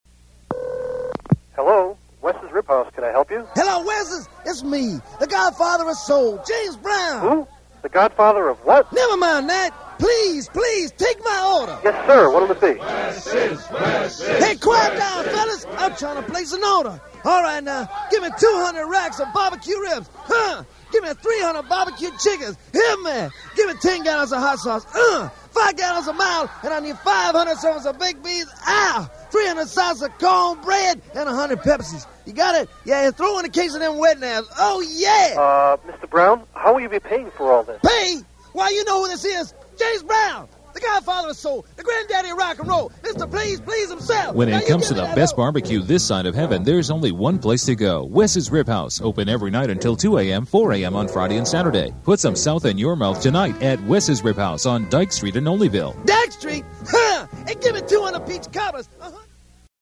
Side B: Commercials